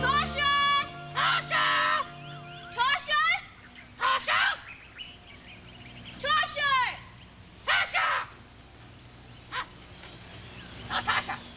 Here, he mimics Annie's tone of voice as she calls for Tasha.